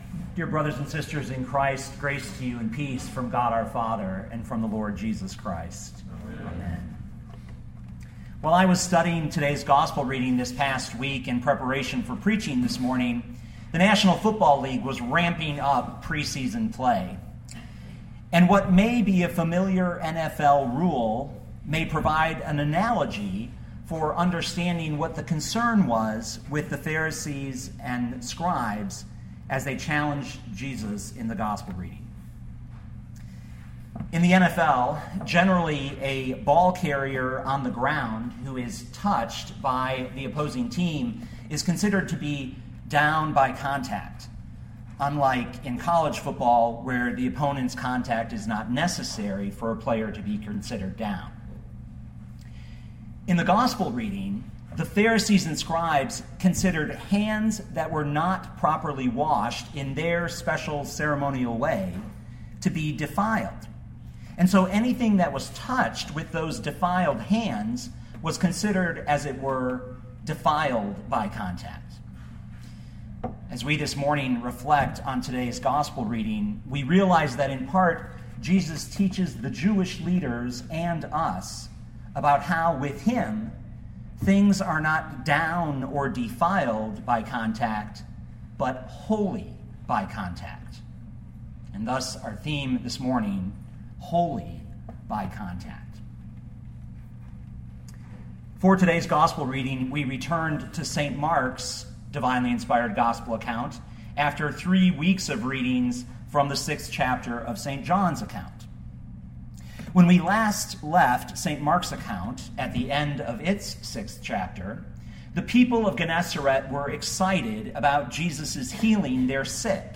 2015 Mark 7:1-13 Listen to the sermon with the player below, or, download the audio.